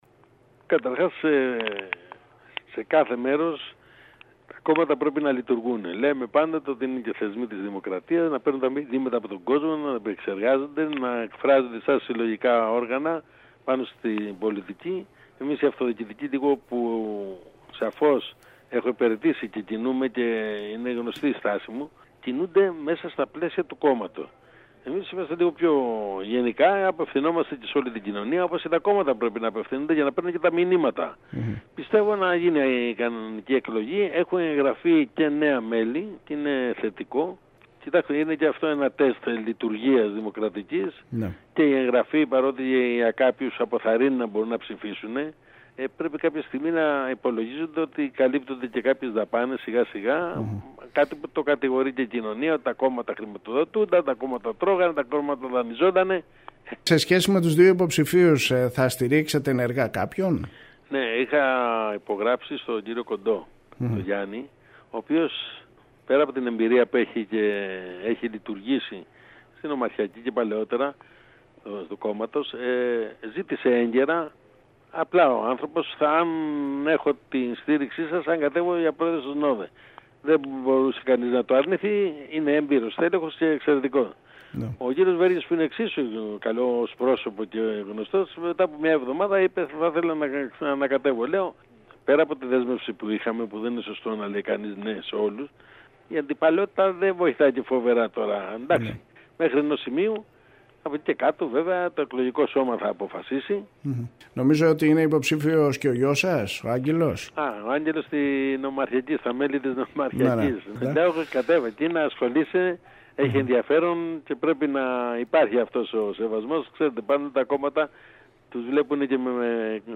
Την ανάγκη συμμετοχής των πολιτών στις πολιτικές διεργασίες αναμόρφωσης των κομμάτων, όπως είναι οι εσωκομματικές εκλογές στη ΝΔ, εξέφρασε ο πρώην βουλευτής του κόμματος Σπύρος Σπύρου μιλώντας στην ΕΡΤ Κέρκυρας. Η όσμωση των κομμάτων με την κοινωνία μέσω των κομματικών οργανισμών μπορεί να μεταφέρει ζητήματα της βάσης στην κορυφή της κομματικής πυραμίδας, όπως επισήμανε.